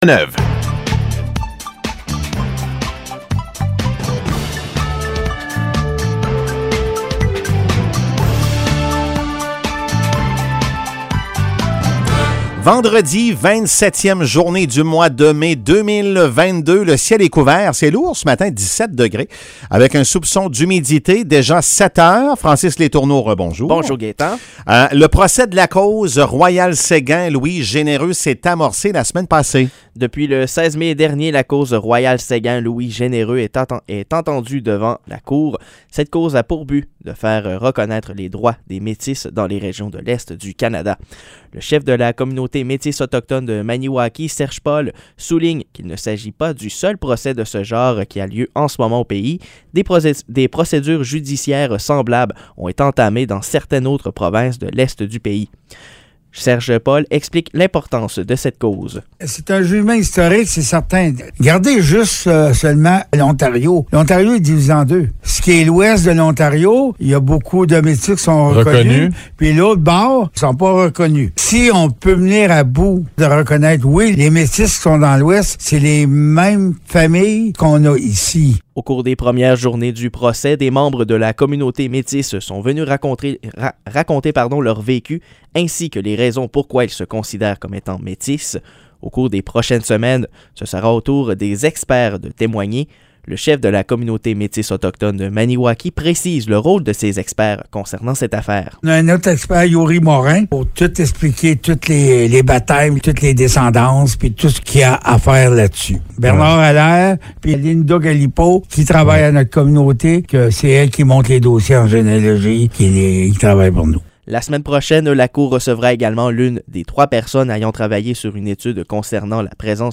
Nouvelles locales - 27 mai 2022 - 7 h